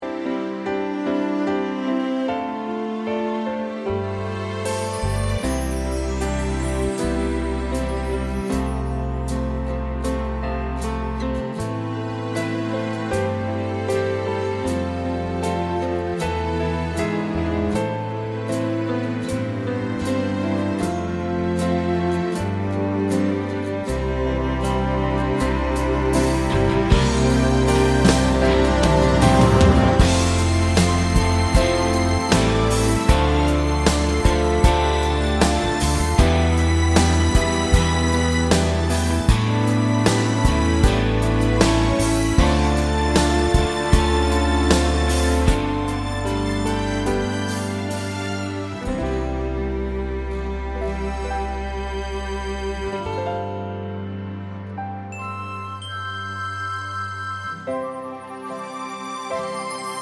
Live Version Easy Listening 3:04 Buy £1.50